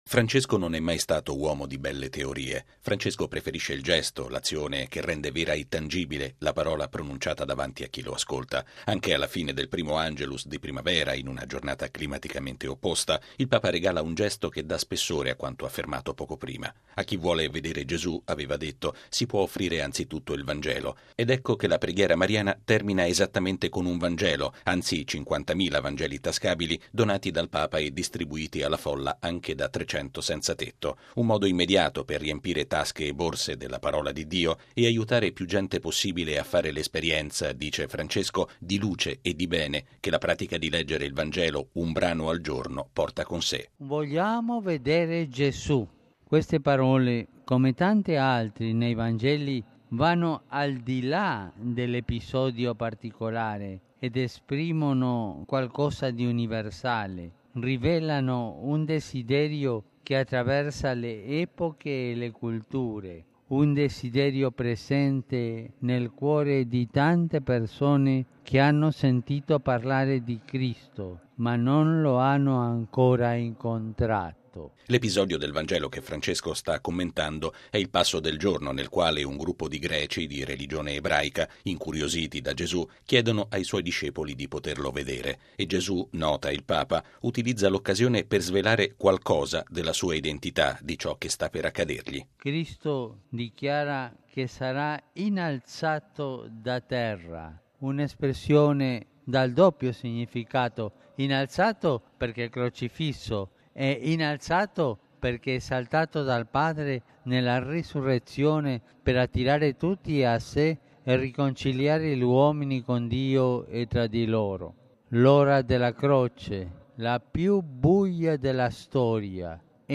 È l’augurio che il Papa ha espresso all’Angelus, che Francesco ha concluso con una nuova distribuzione di Vangeli tascabili alla folla. Il Papa si è poi appellato al mondo perché l’accesso all’acqua sia libero a tutti senza discriminazioni. Il servizio